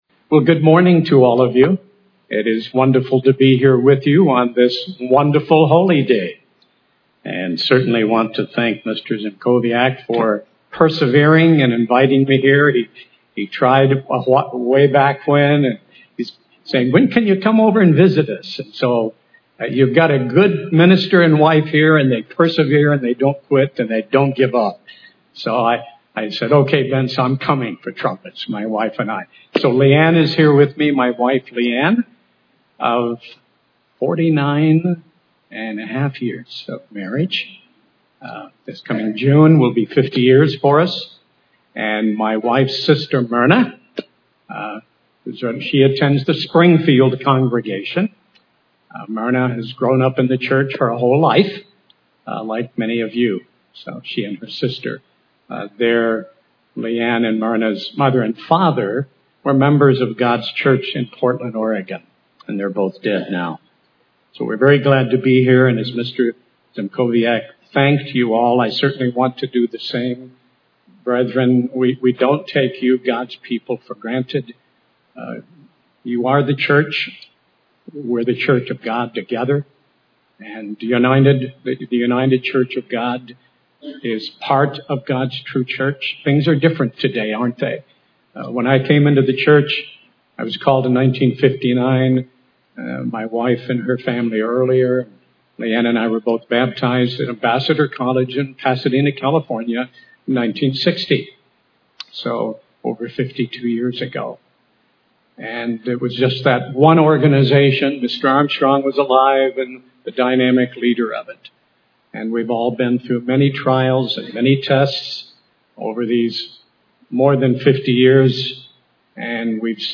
This sermon was given on the Feast of Trumpets.